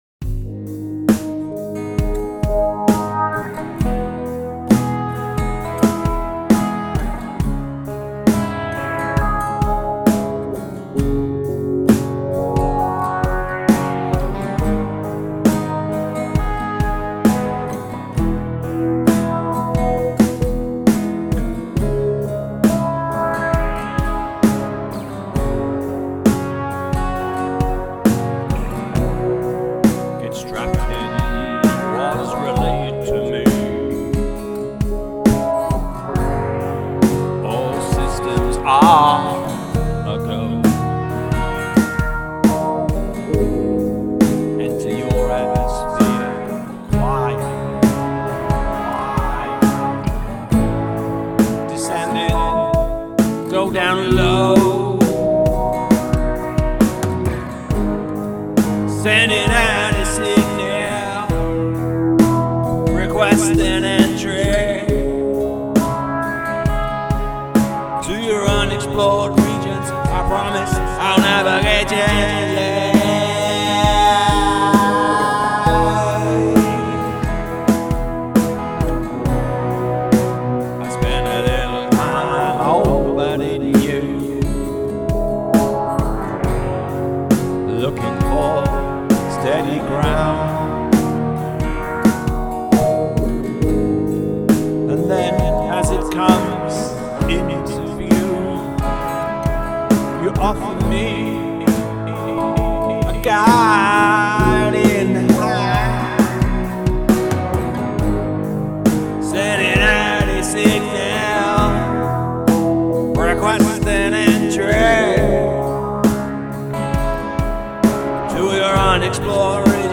Has there ever been a more beautiful album closer?